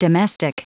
Prononciation du mot domestic en anglais (fichier audio)
Prononciation du mot : domestic